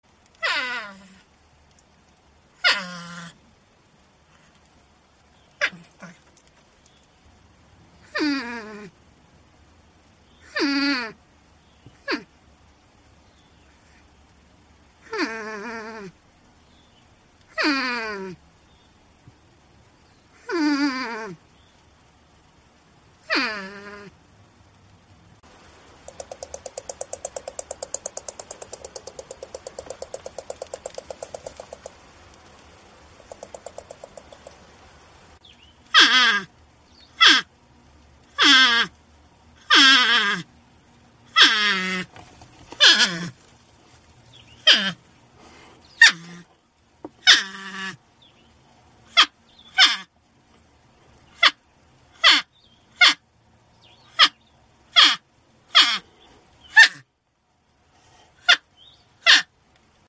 Tiếng con Nhím kêu MP3